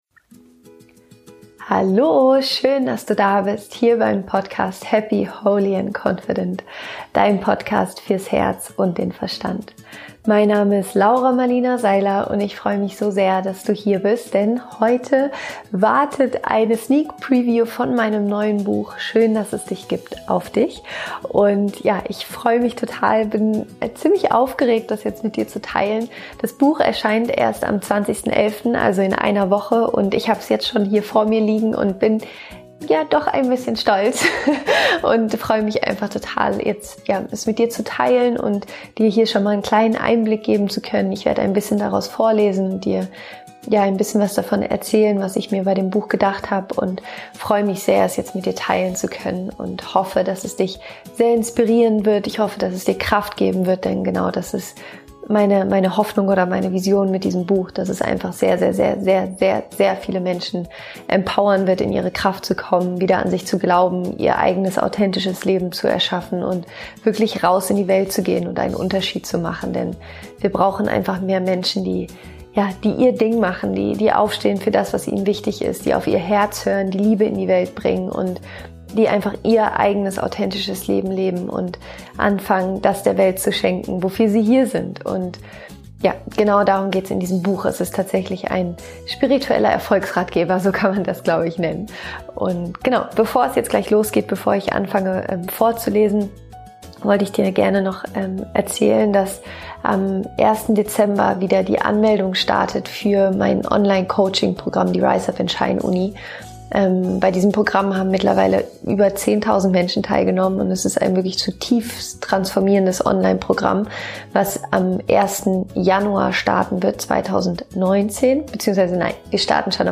Weil ich es solange aber nicht mehr aushalte, gebe ich dir heute schon einen kleinen Einblick in das Buch, erzähle dir, worum es darin geht und lese dir ein bisschen daraus vor.